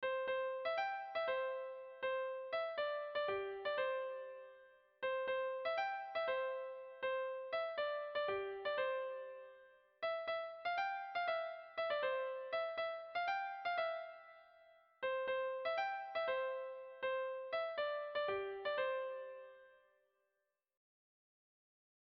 Sentimenduzkoa
Zortziko txikia (hg) / Lau puntuko txikia (ip)
A-A-B-A